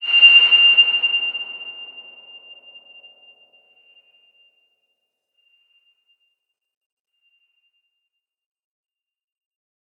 X_BasicBells-F5-mf.wav